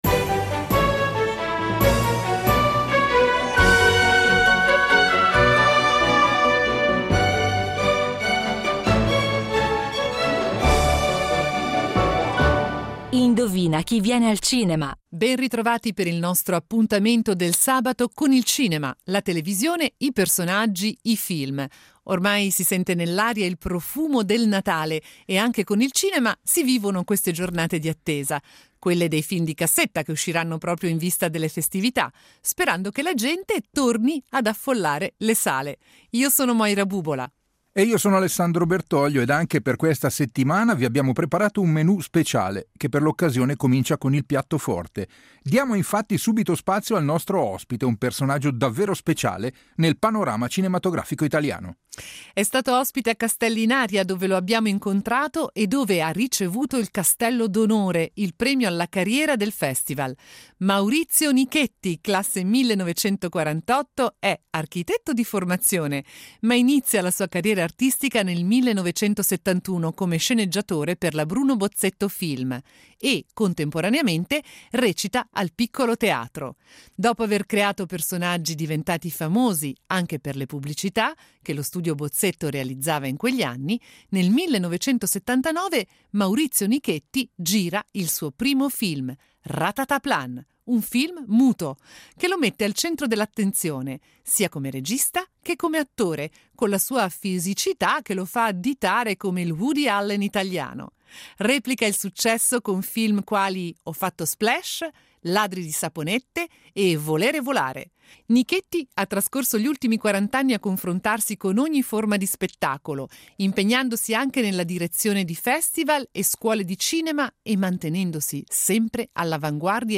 Un’intervista esclusiva a Maurizio Nichetti